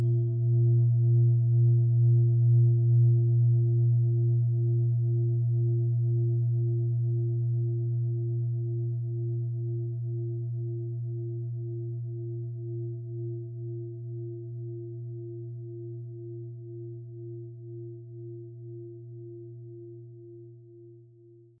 Planetenton
Ein unpersönlicher Ton.
MaterialBronze